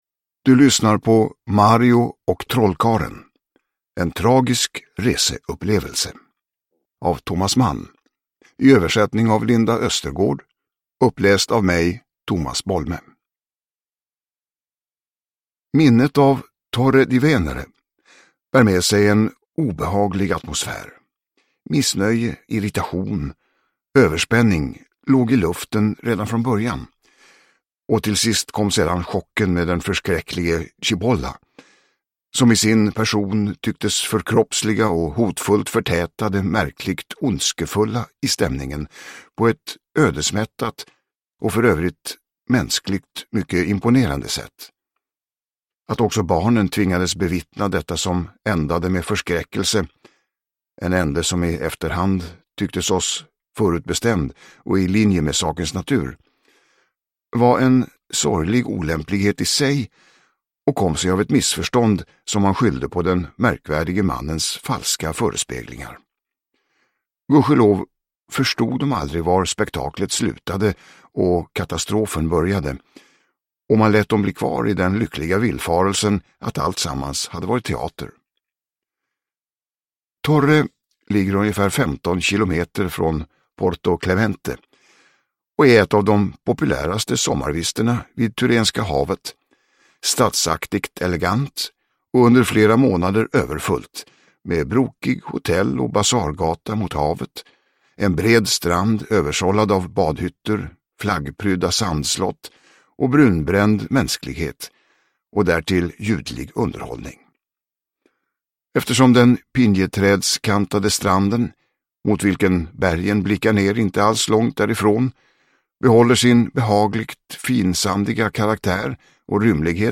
Mario och trollkarlen – Ljudbok – Laddas ner
Uppläsare: Tomas Bolme